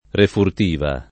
refurtiva [ refurt & va ] s. f.